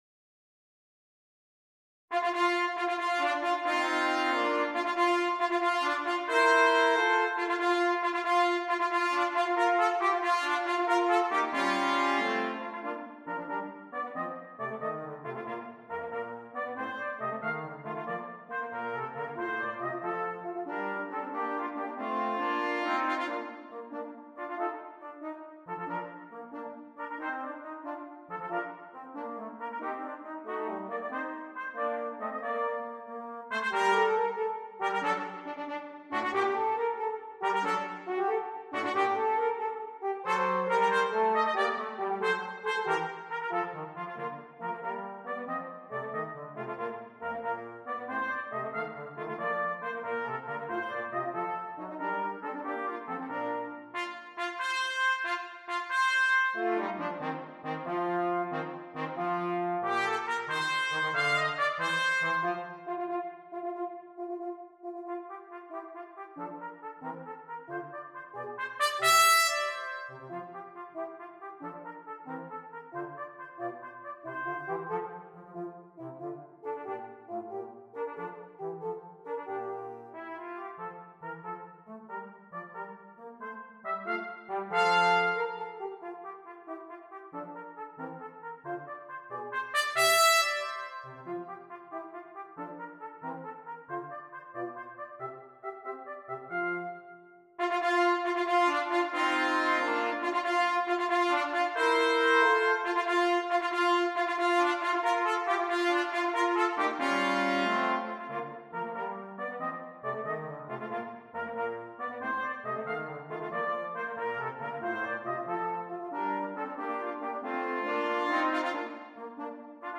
Brass Trio
Each player has something interesting and fun to play.